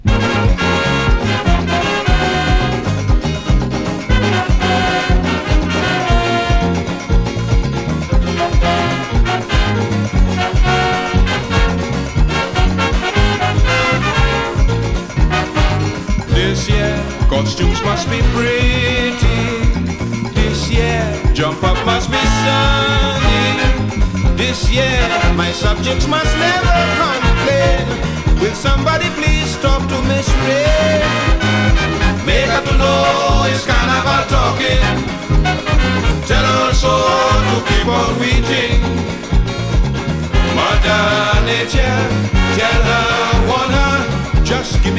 Calypso, Soca, Disco, Soul　USA　12inchレコード　33rpm　Stereo